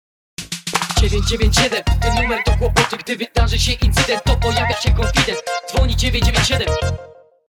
nowy dzwonek na telefon